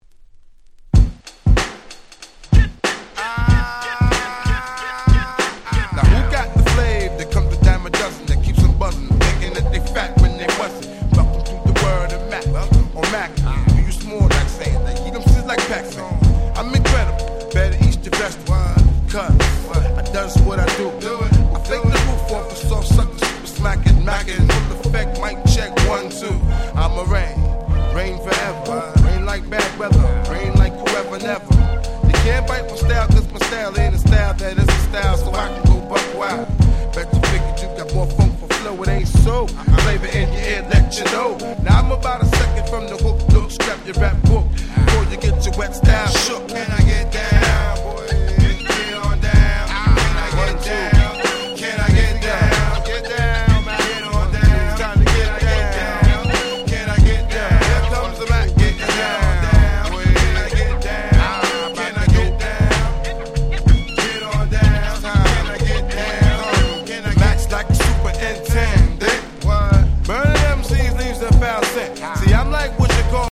95' Smash Hit Hip Hop !!
90's Boom Bap ブーンバップ